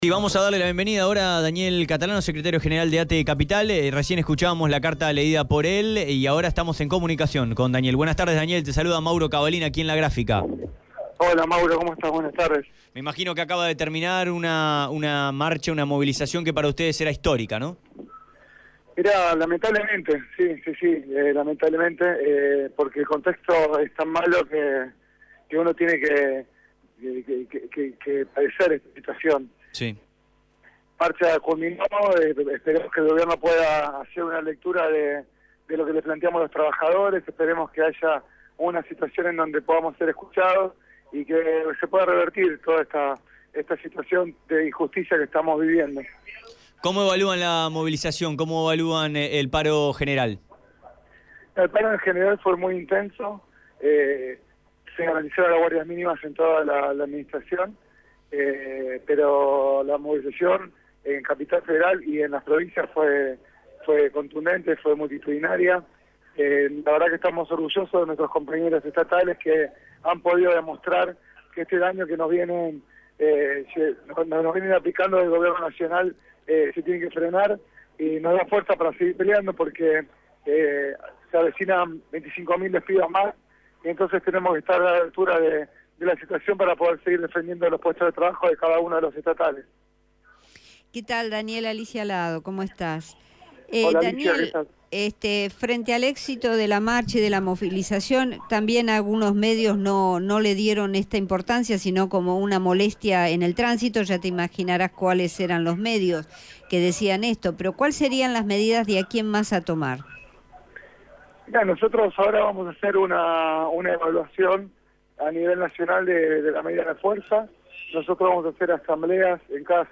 quien en comunicación telefónica  sostuvo «esperamos que el gobierno pueda hacer una lectura de lo que le planteamos los trabajadores